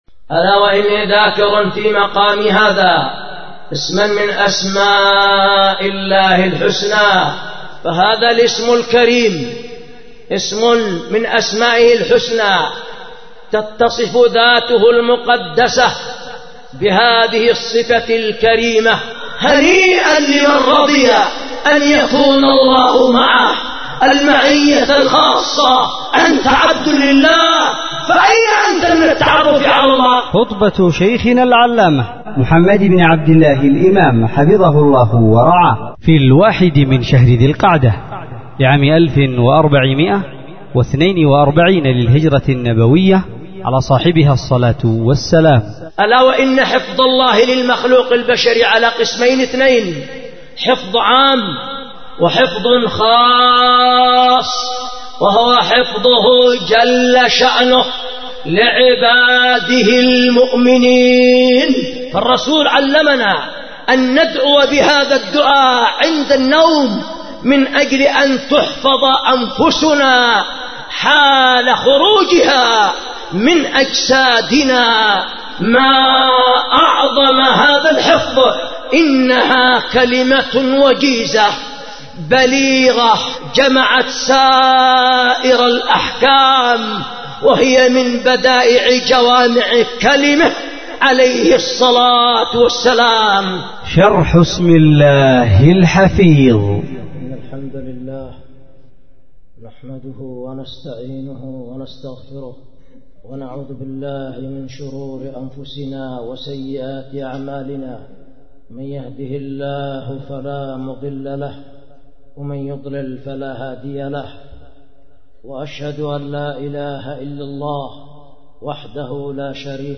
شرح اسم الله الحفيظ خُطبة لفضيلة الشيخ العلامة
ألقيت بـ دار الحديث بمعبر حرسها الله ذمار_اليمن